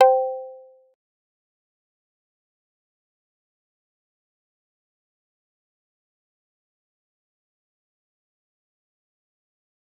G_Kalimba-C5-pp.wav